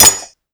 Shield1.wav